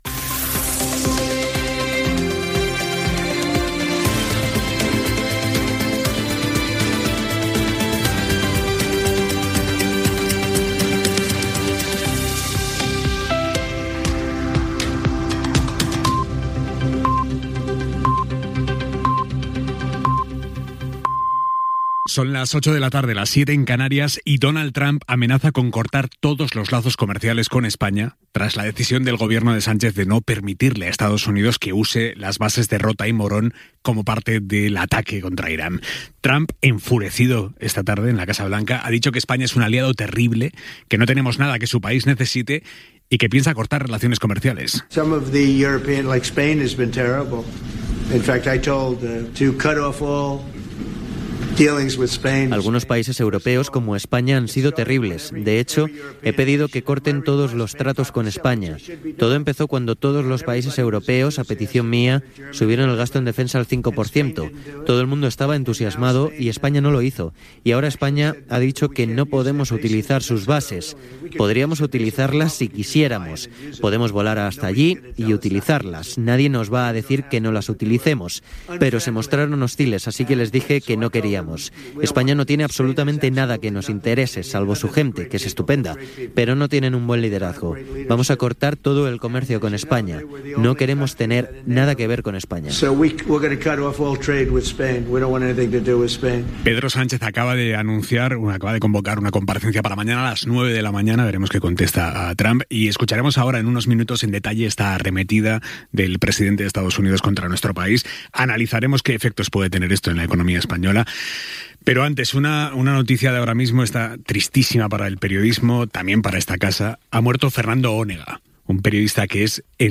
Senyals horaris, hora, notícies sobre Donald Trump i Espanya arran de la guerra de l'Iran. Comunicació de la mort del periodista Fernando Ónega i paraules d'Iñaki Gabilondo.
Informatiu